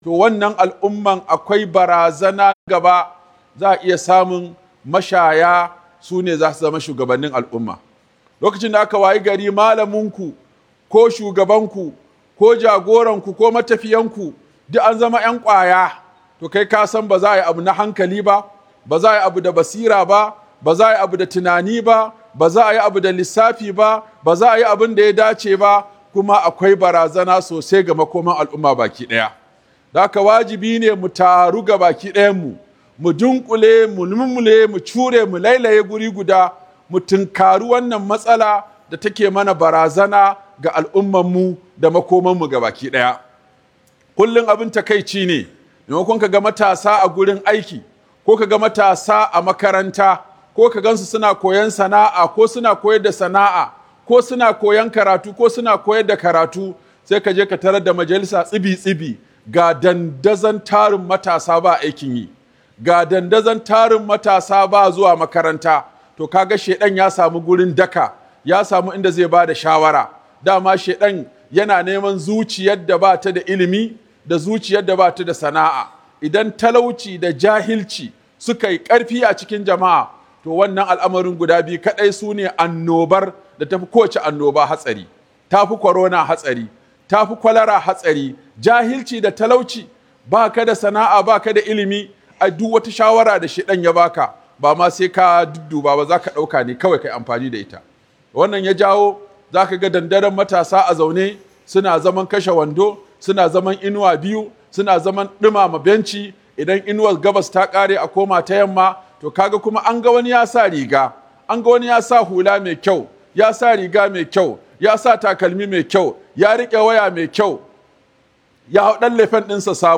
Muhimmancin Sana'a da Dogaro da Kai ga Matasa - Huduba by Sheikh Aminu Ibrahim Daurawa